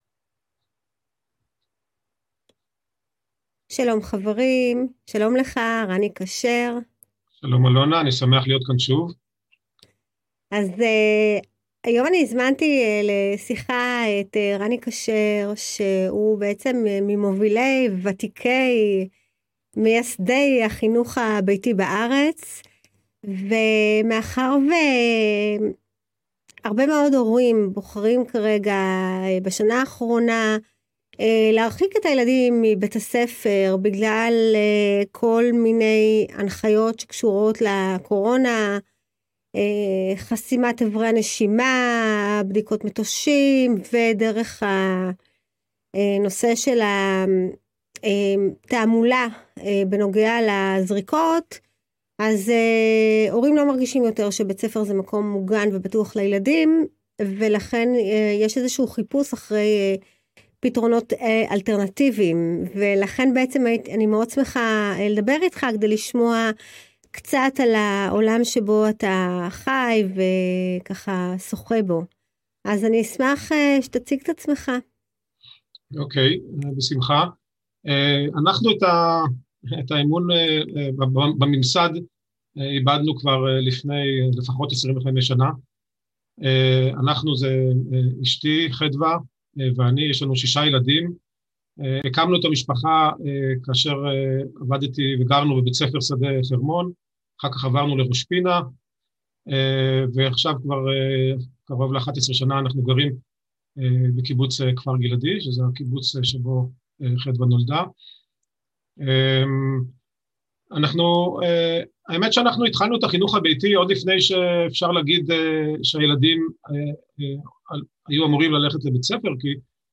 הקלטת מפגש הזום